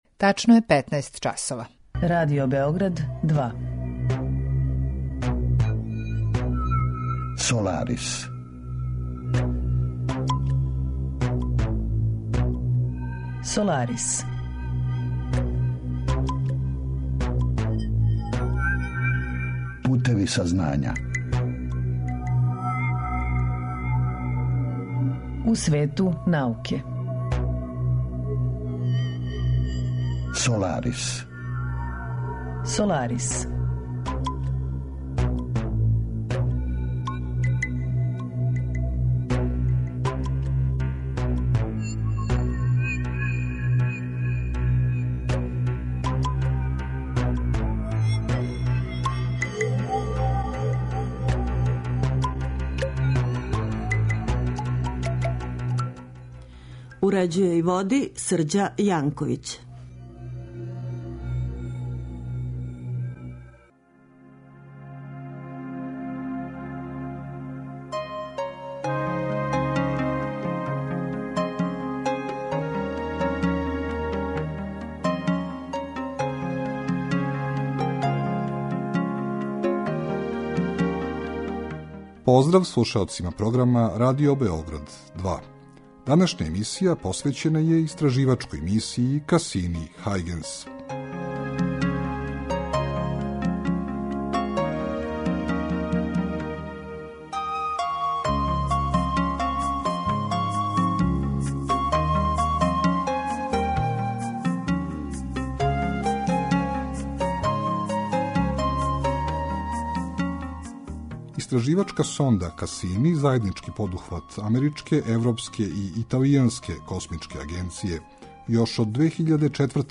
саговорник: дипломирани инжењер